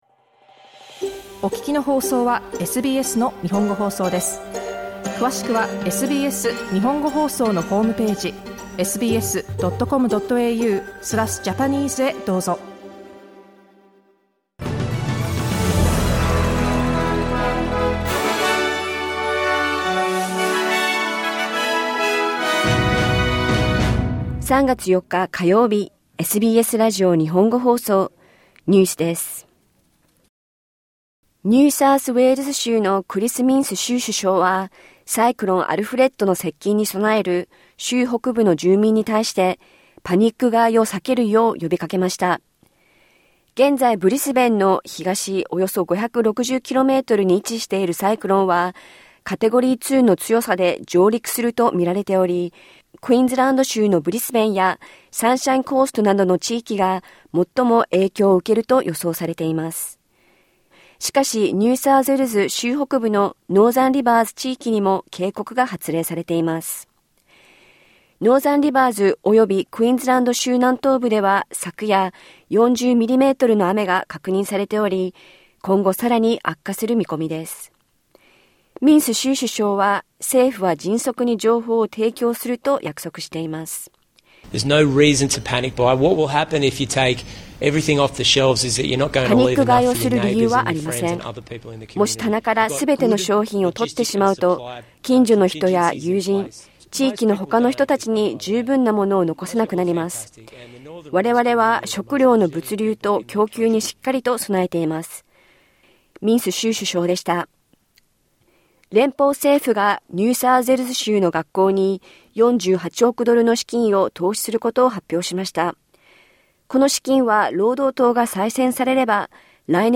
サイクロン・アルフレッドが接近する中、地域の住民に対して、パニック買いを避けるよう、呼びかけられています。ニューサウスウェールズ州の公立学校に対し、数十億ドル規模の投資が発表されました。午後１時から放送されたラジオ番組のニュース部分をお届けします。